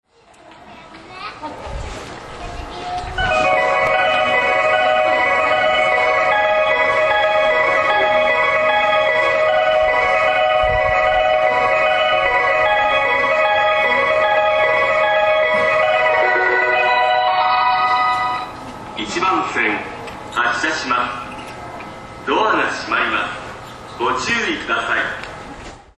2006年４月にメロディーが変更され、音程が低いものとなりました。
Gota del Vient 音程が低くなっています